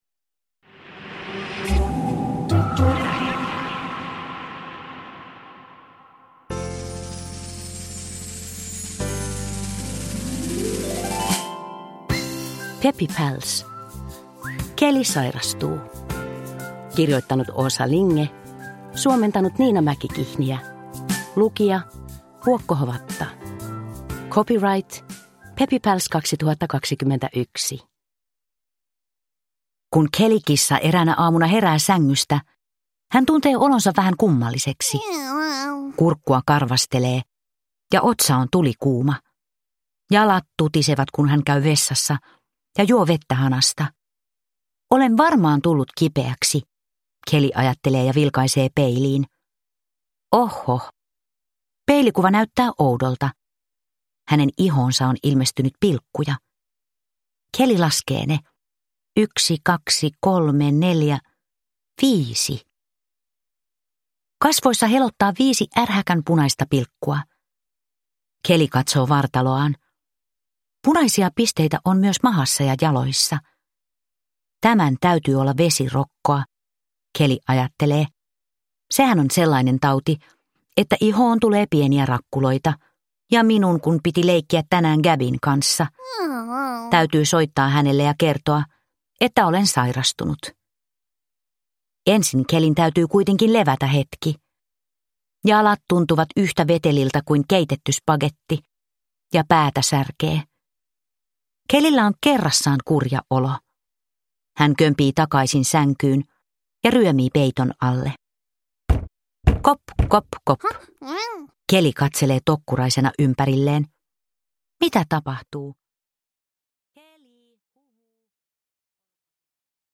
Peppy Pals: Kelly sairastuu – Ljudbok – Laddas ner